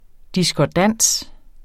Udtale [ diskɒˈdanˀs ]